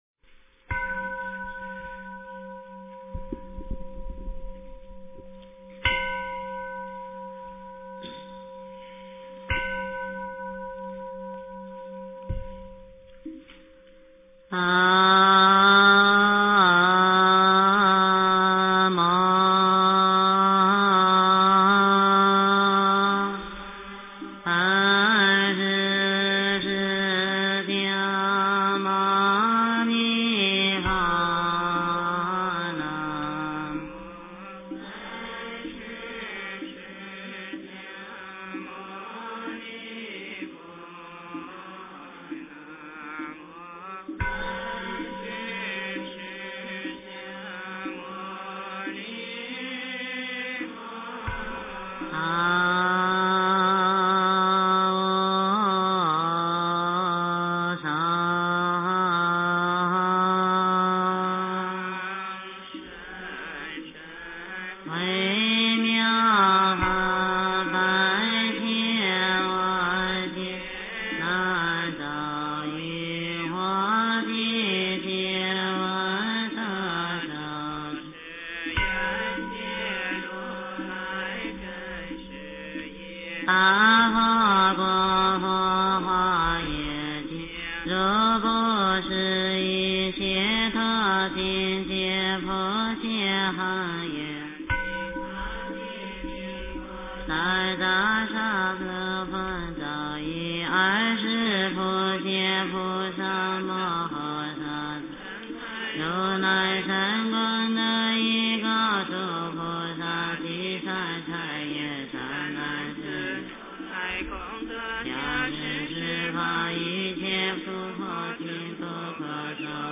普贤菩萨行愿品--普寿寺 经忏 普贤菩萨行愿品--普寿寺 点我： 标签: 佛音 经忏 佛教音乐 返回列表 上一篇： 早课--万佛圣城梵呗 下一篇： 四大祝延--普陀山梵唄 相关文章 大乘金刚般若宝忏法卷下--金光明寺 大乘金刚般若宝忏法卷下--金光明寺...